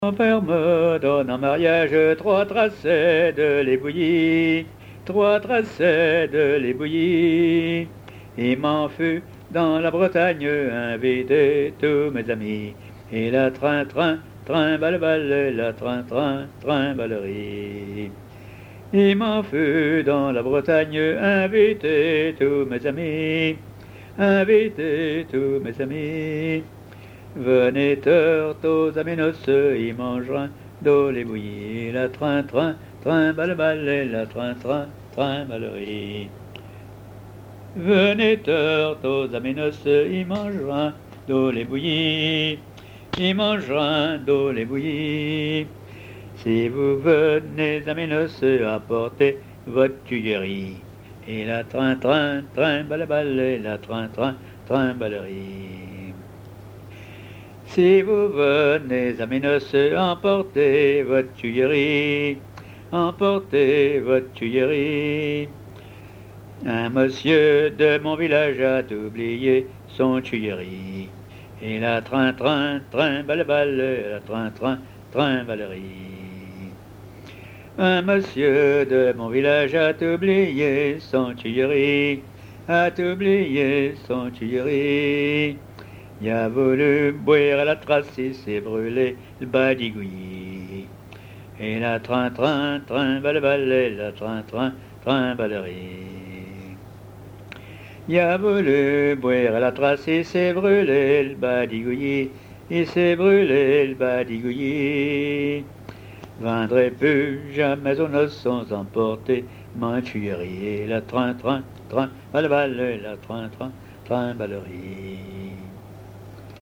chansons à ripouner ou à répondre
Pièce musicale inédite